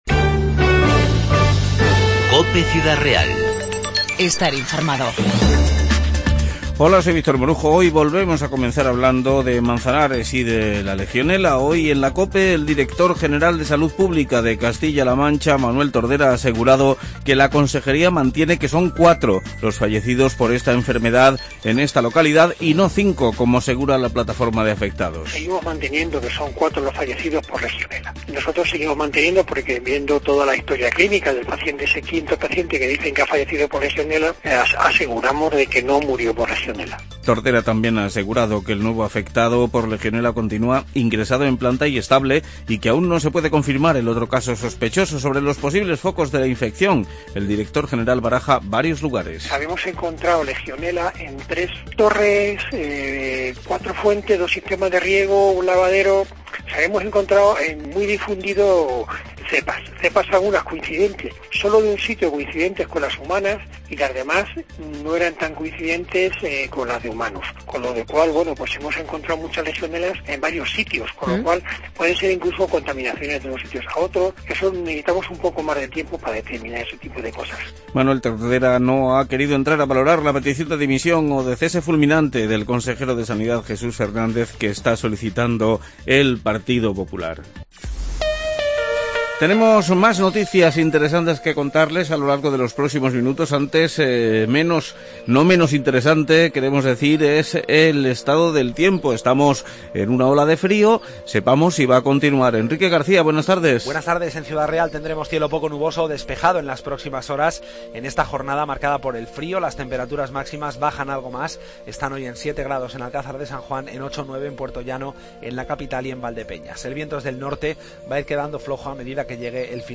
INFORMATIVO 16-2-16